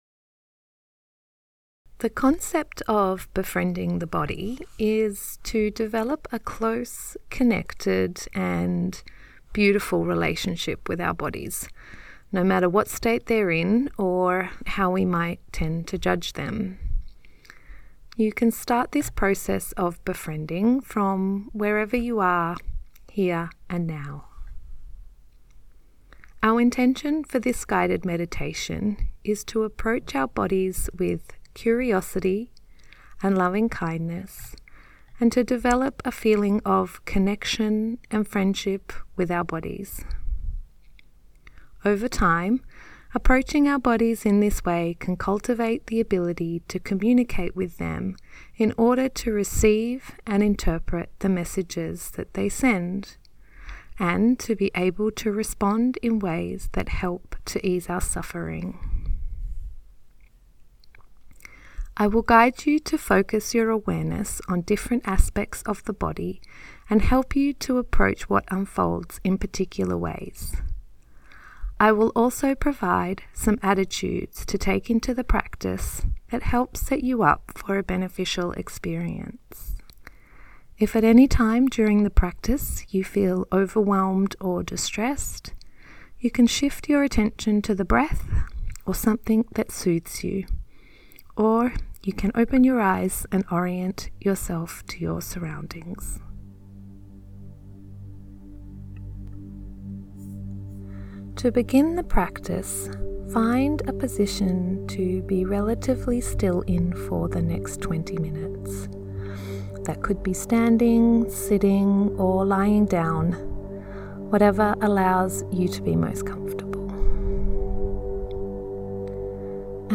guided meditations